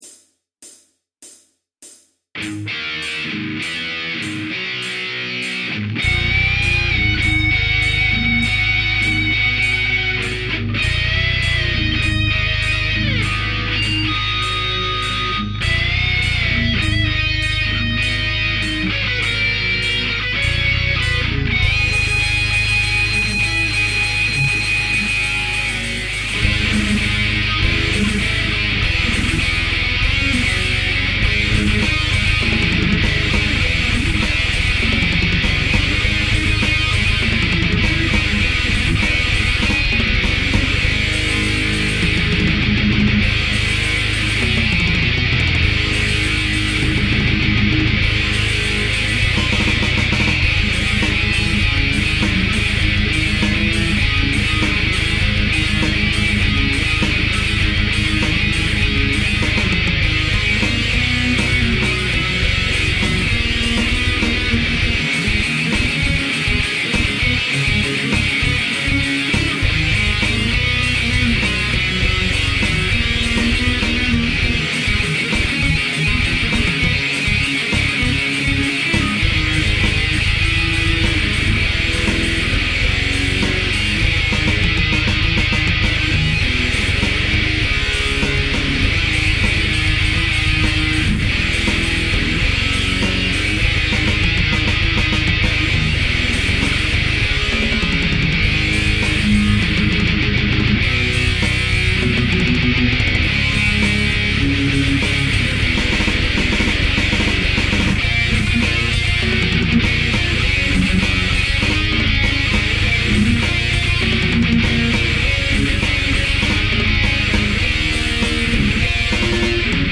A guitarist
German Heavy Metal